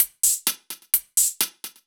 Index of /musicradar/ultimate-hihat-samples/128bpm
UHH_ElectroHatC_128-01.wav